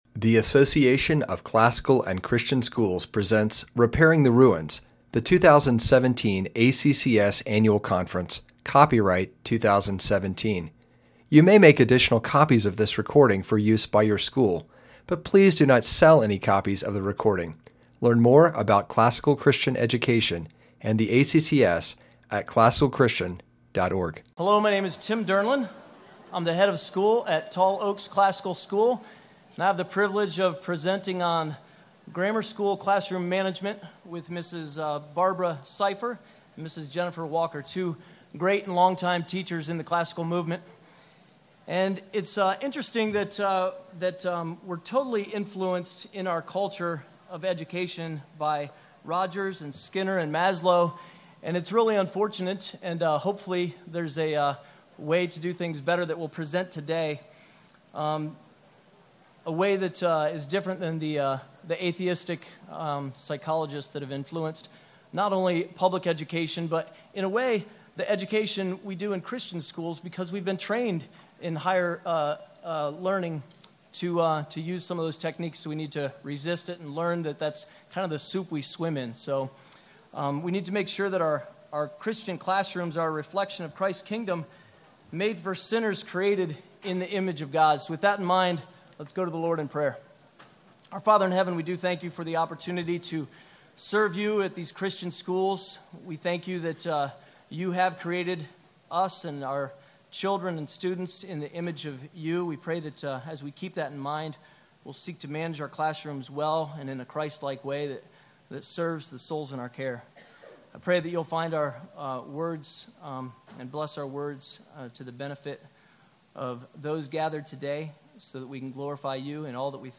2017 Foundations Talk | 1:04:11 | K-6, General Classroom
This workshop features two teachers and one administrator giving practical advice for classroom management.